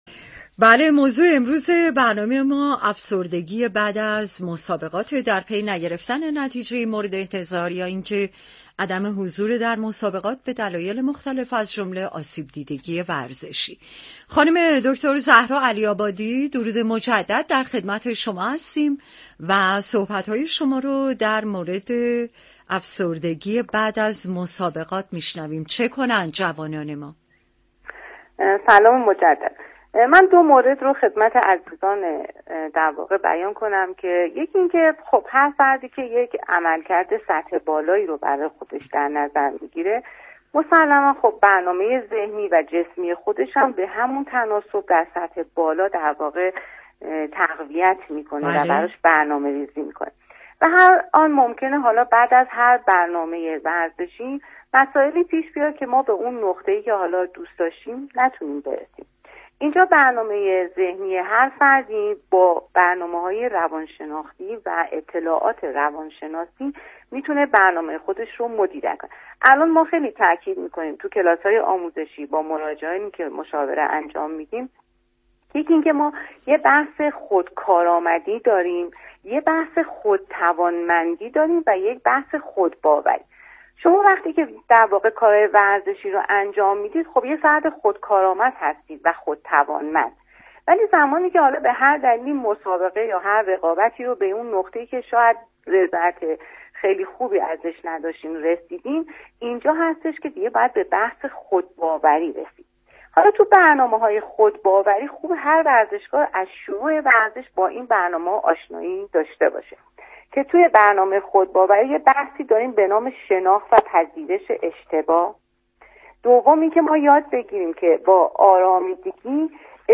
/مصاحبه/